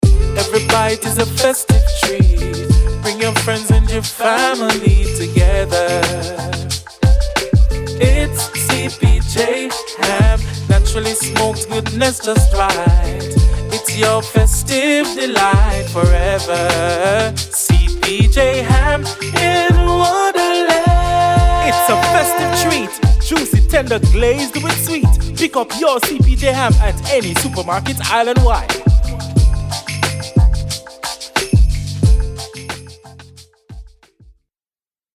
This enchanting Christmas campaign was thoughtfully crafted to highlight the company's exceptional ham special, enticing consumers all across Jamaica. With a vibrant production direction, we aimed to capture the joyous essence of the holiday season, creating a warm and festive atmosphere that resonates with the spirit of Christmas.